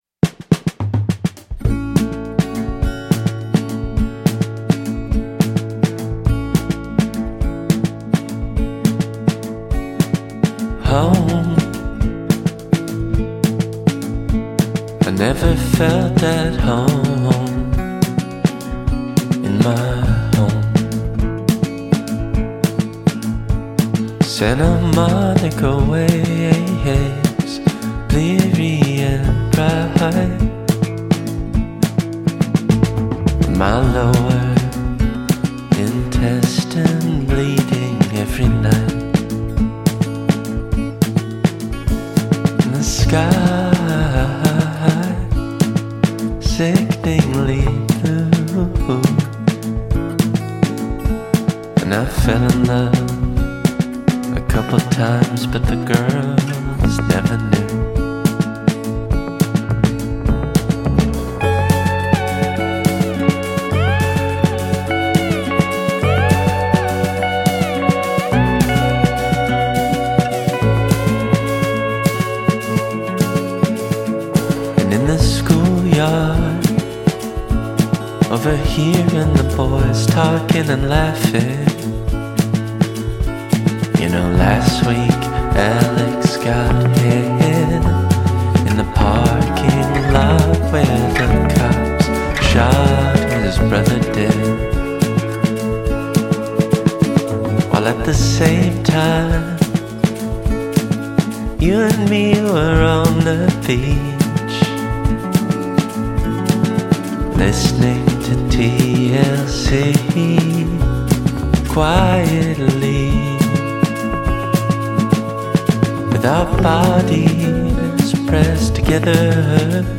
поп/софт рок.